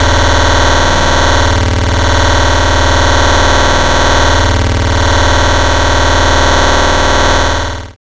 56.5 SFX.wav